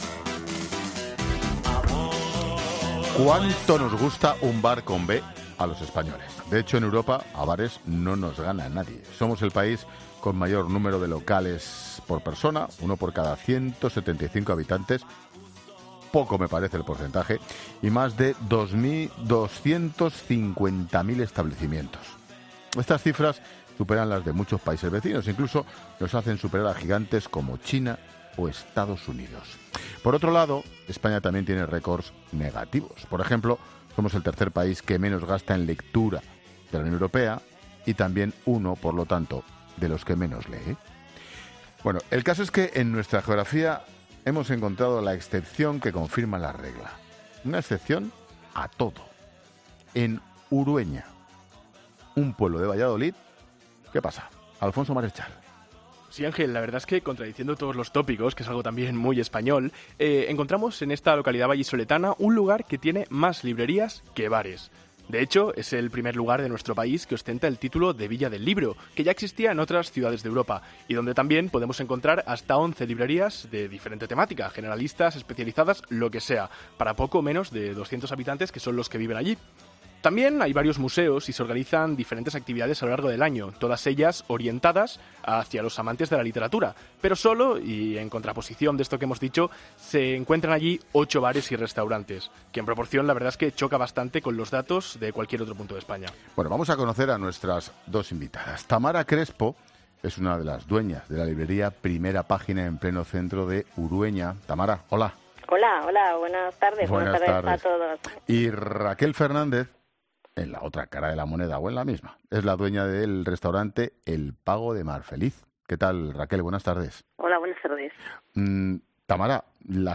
En 'La Tarde' hemos hablado con las dueñas de una librería y de un bar para saber más acerca del pueblo y de su estilo de vida